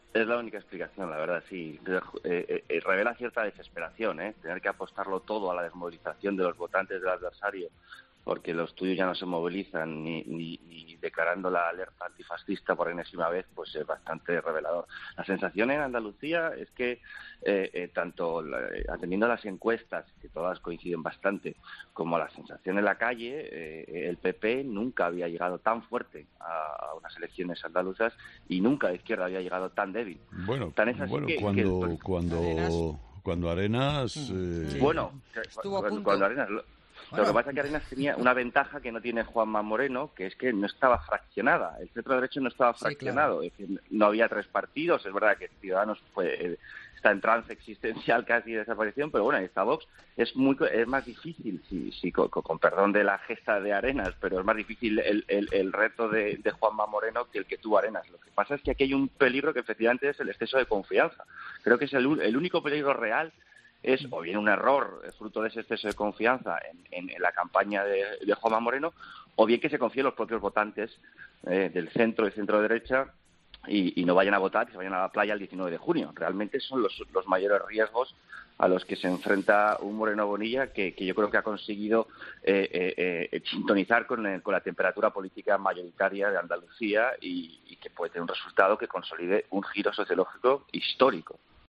El colaborador de 'Herrera en COPE' ha analizado la actualidad de las elecciones andaluzas